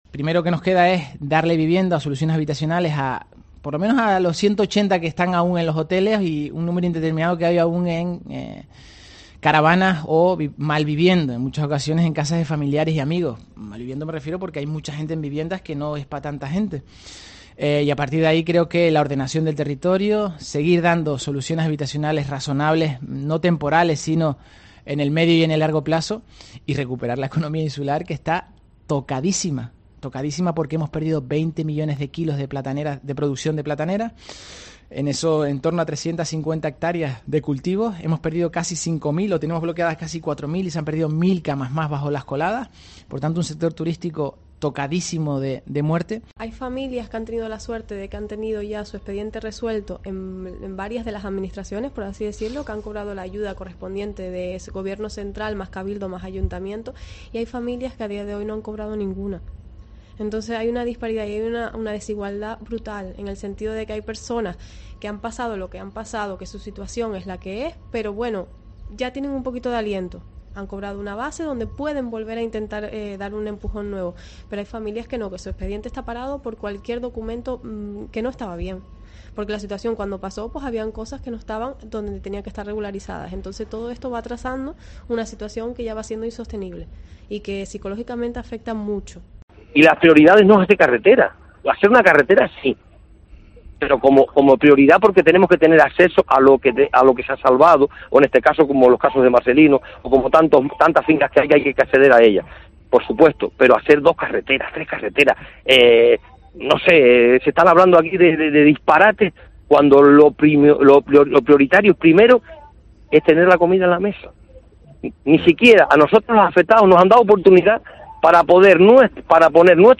Sonidos en el aniversario de la última erupción de Cumbre Vieja